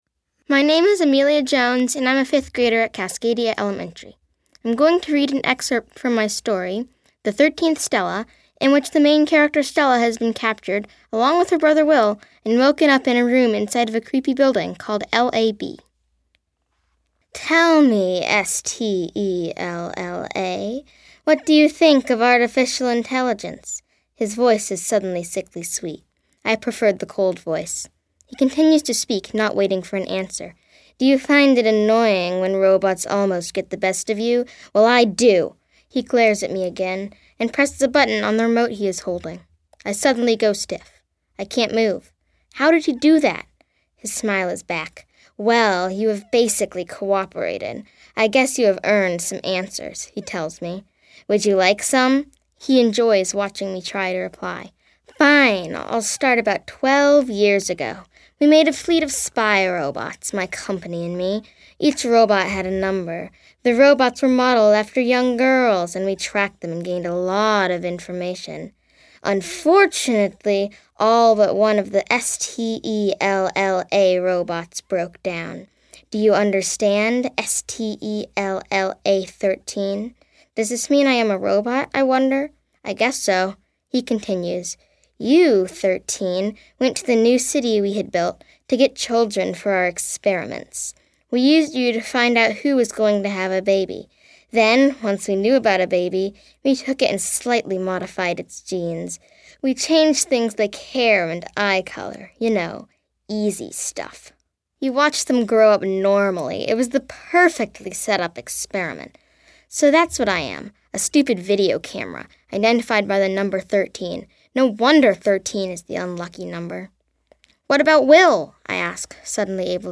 This year, MoPop and Jack Straw Cultural Center collaborated to provide winners of their annual Write Out of This World Writing Contest with a unique experience: Winners participated in a writing workshop, voice workshop, and recording session at Jack Straw, resulting in a professional recording of each writer reading their work.